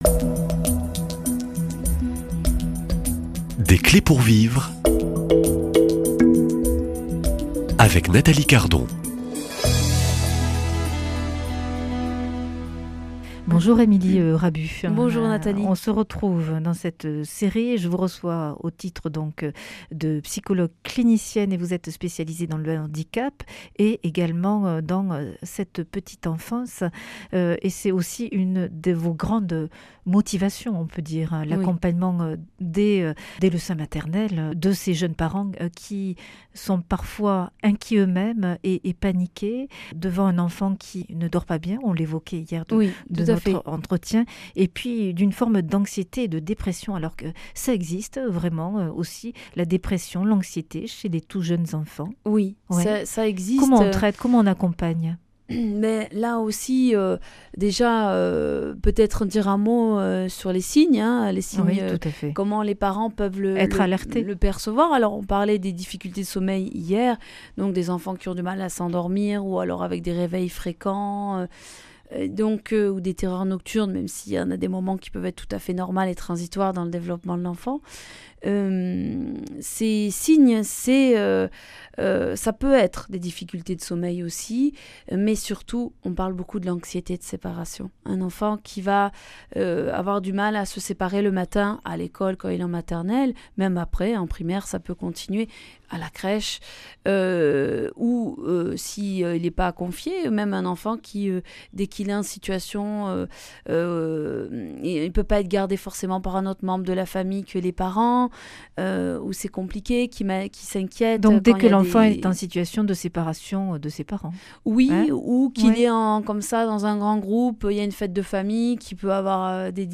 psychologue clinicienne
Une émission présentée par